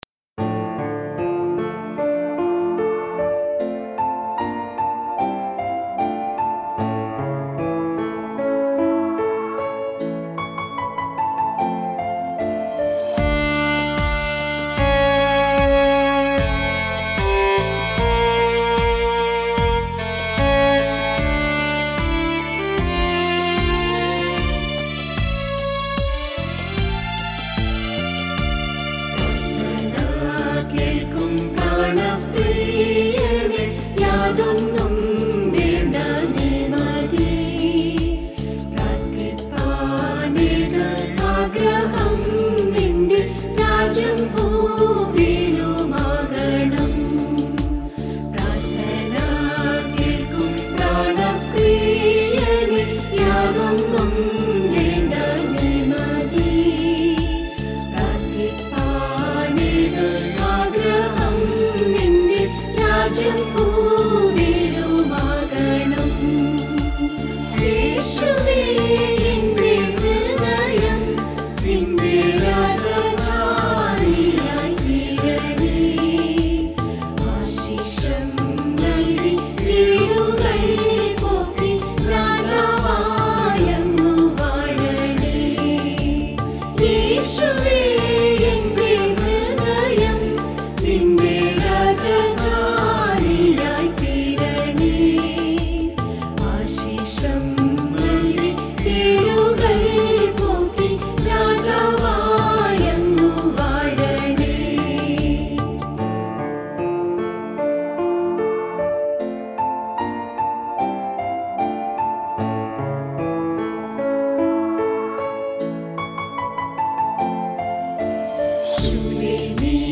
Devotional Songs Jan 2021 Track 6